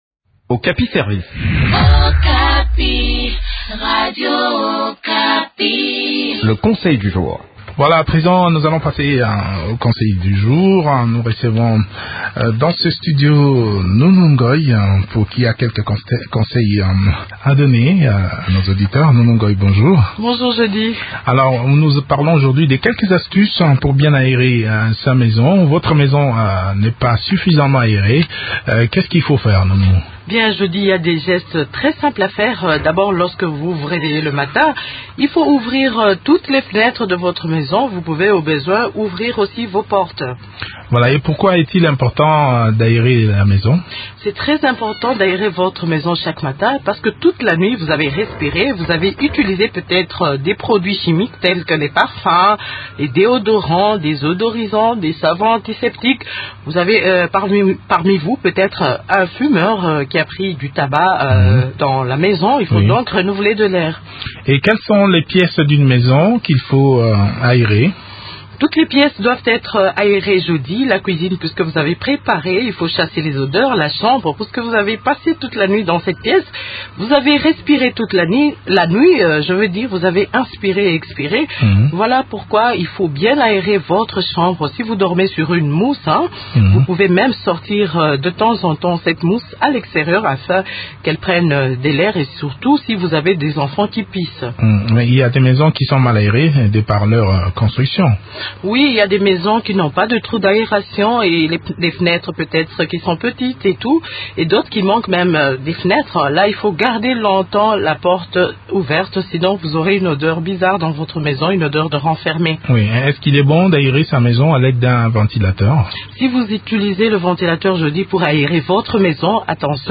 Okapi service, Émissions / anémie, Anémie SS, drépanocytose